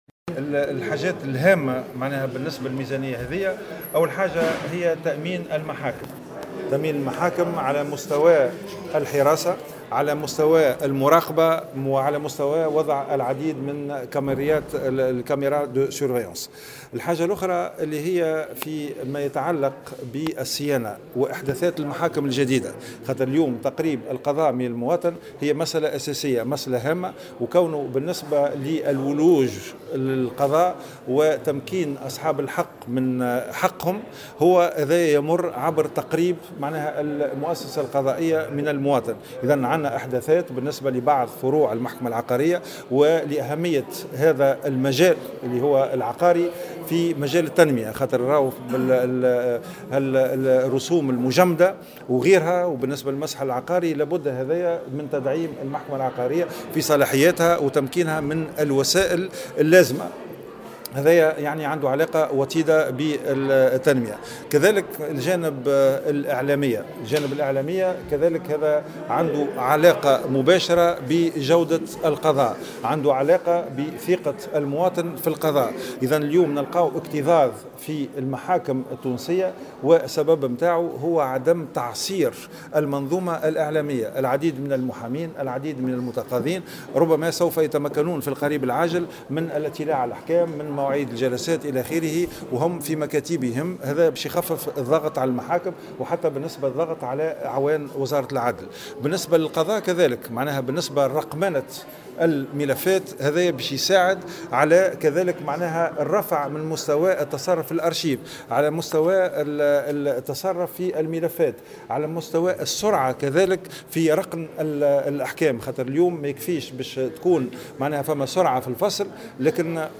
أكد وزير العدل غازي الجريبي، في تصريح لمراسل الجوهرة أف أم، على هامش حضوره في مجلس النواب، اليوم الأربعاء، أن الاعتمادات المرصودة لوزارته ستخصص في جانب منها لتأمين المحاكم على مستوى الحراسة والمراقبة، وصيانتها وإحداث محاكم جديدة وتعصير المنظومة الإعلامية القضائية.